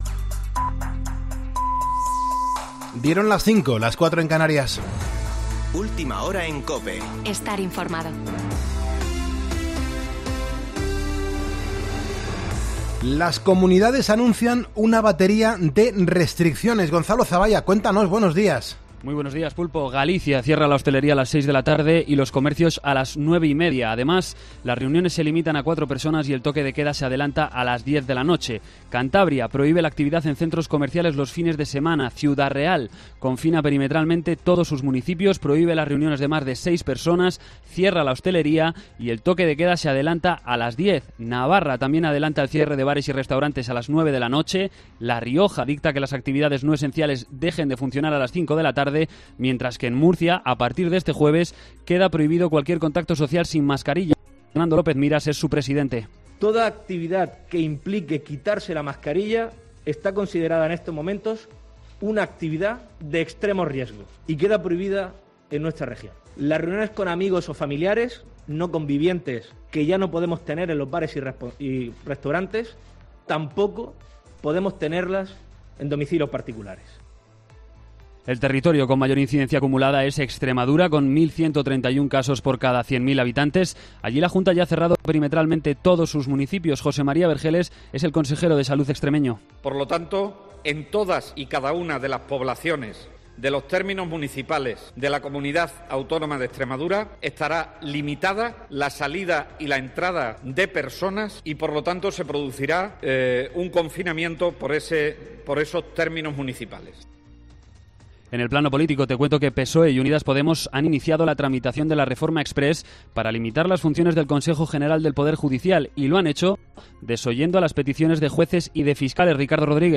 Boletín de noticias COPE del 14 de enero de 2020 a las 05.00 horas